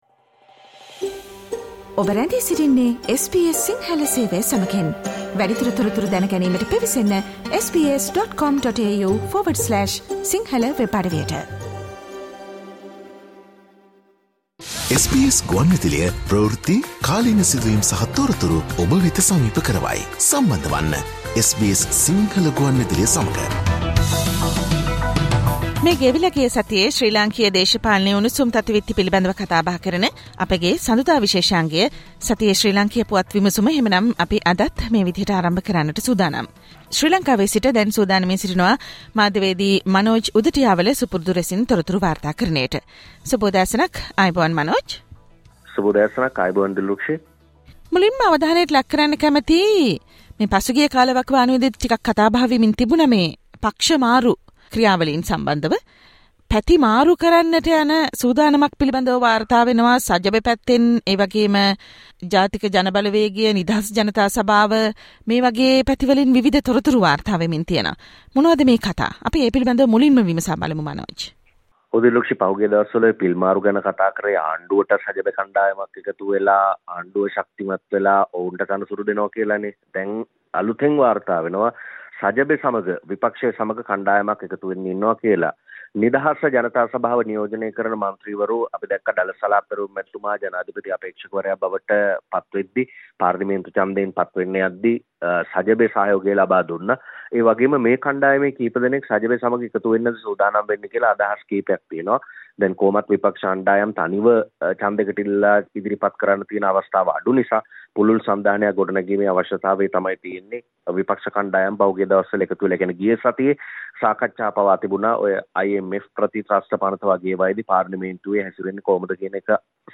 SBS Sinhala radio brings you the most prominent political news highlights of Sri Lanka in this featured Radio update on every Monday.